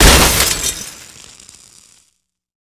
smash.wav